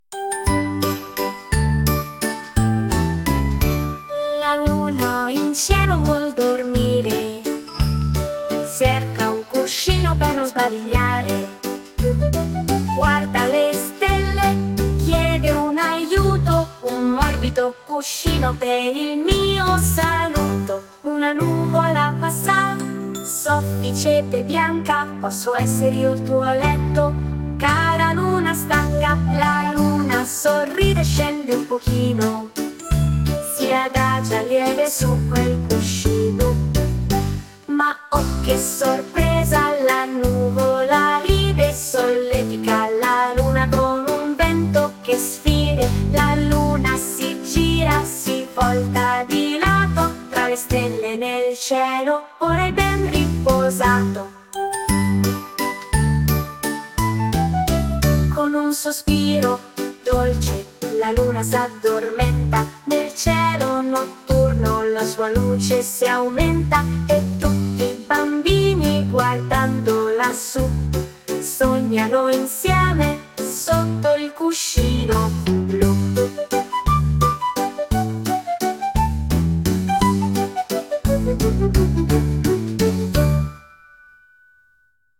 La filastrocca della luna e del suo morbido cuscino